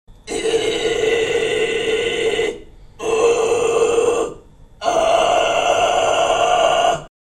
D’un point de vue stylistique, les False Cord Screams sont souvent graves et possèdent une qualité gutturale, souvent bestiale, brutale ou monstrueuse.
False Cord Scream - phonation pressée